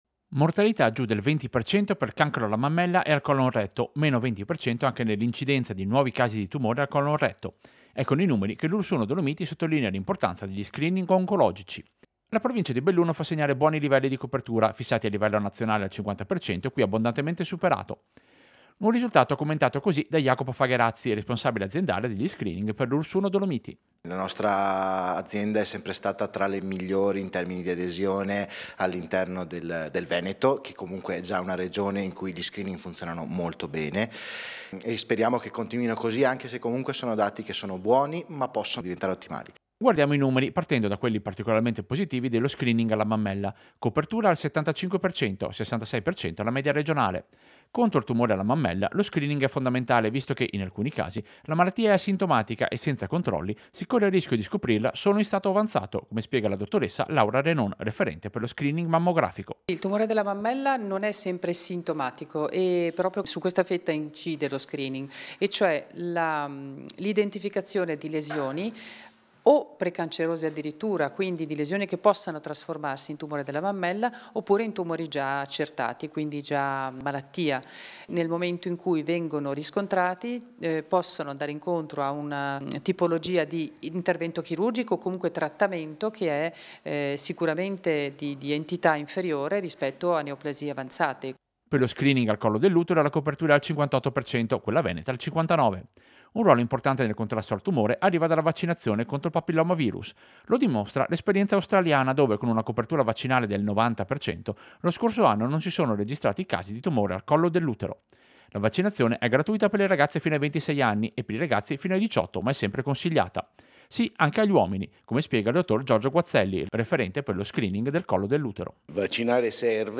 Servizio-Screening-oncologici-Ulss-1-2026.mp3